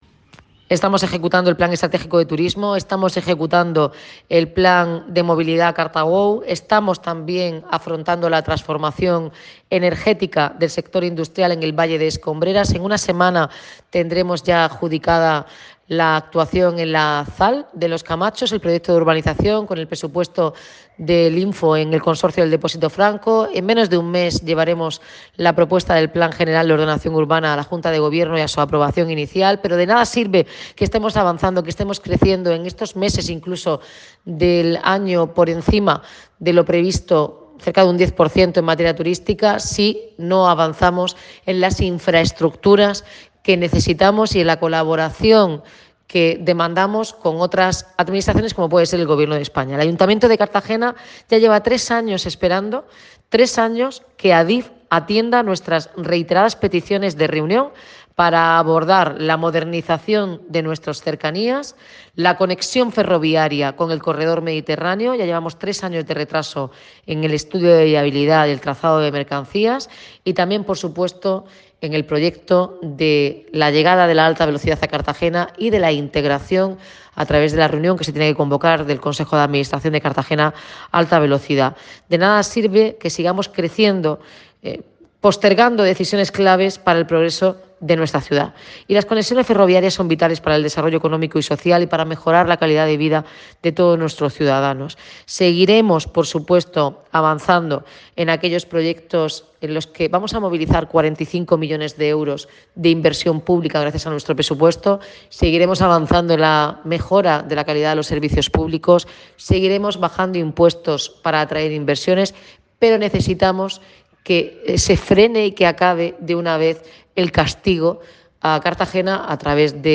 Enlace a Declaraciones de la alcaldesa Noelia Arroyo